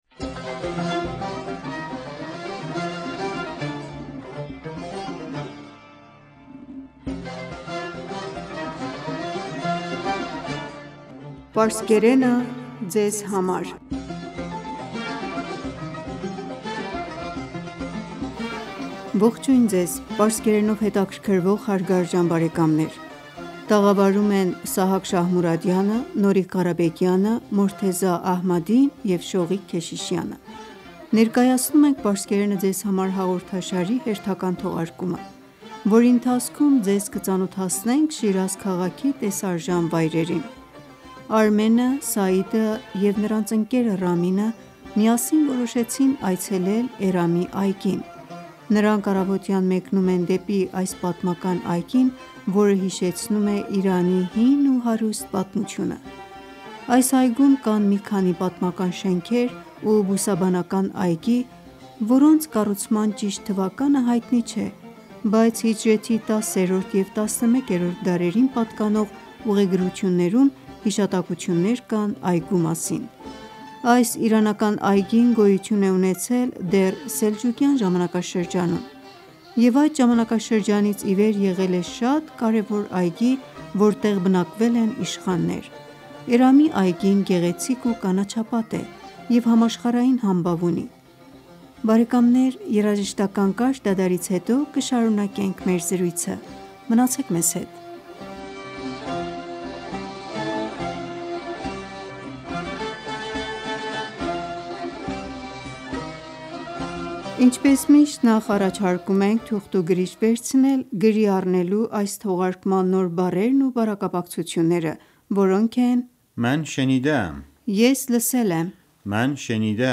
Երաժշտական կարճ դադարից հետո կշարունակենք մեր զրույցը: